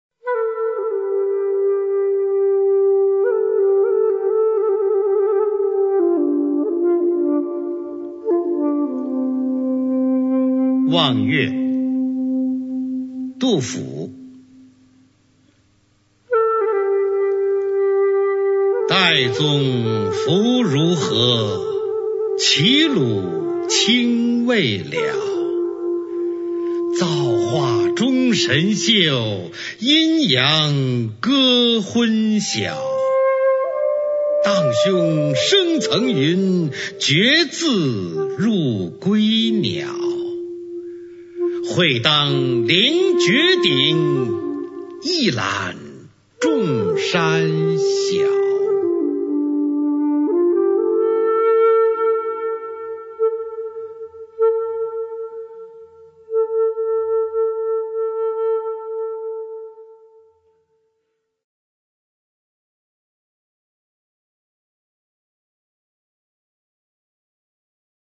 《望岳》原文和译文（含赏析、朗读）